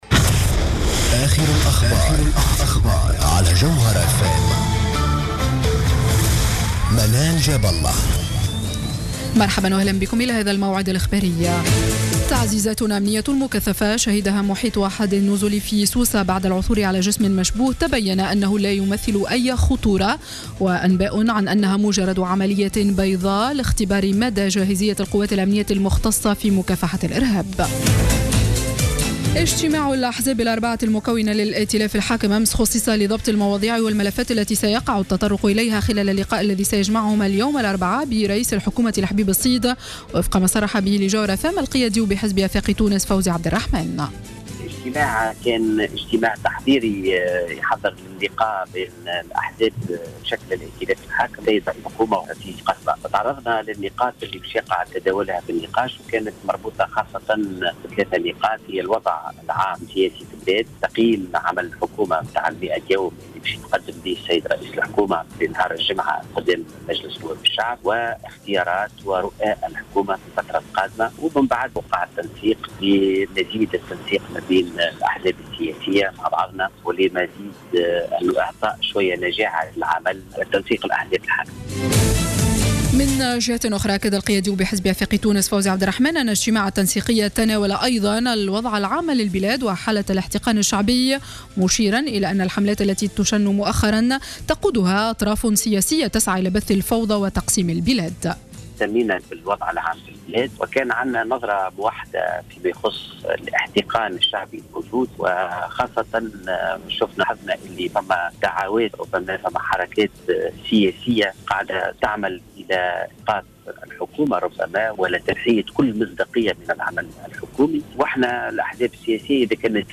نشرة أخبار منتصف الليل ليوم الإربعاء 03 جوان 2015